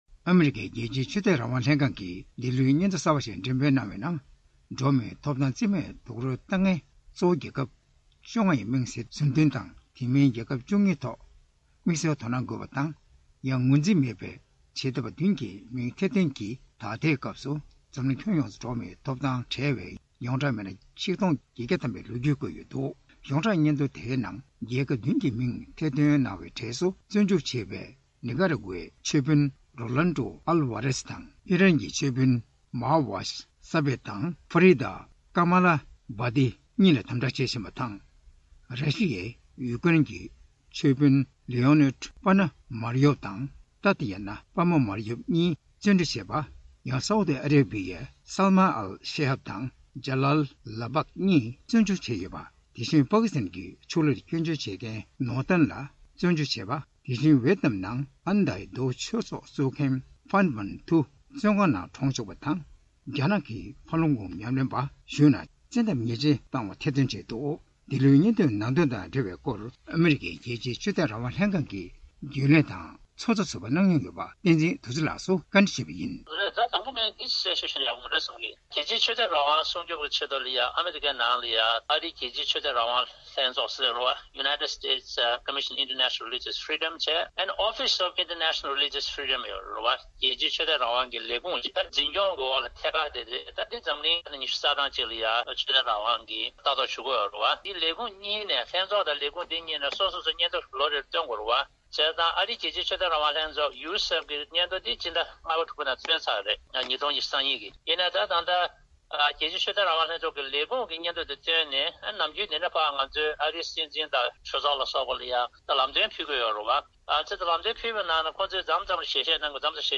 བཀའ་འདྲི་དང་སྦྲགས་ཕྱོཌ་སྒྲིག་ཞུས་པ་ཞིག་གསན་རོགས་གནང་།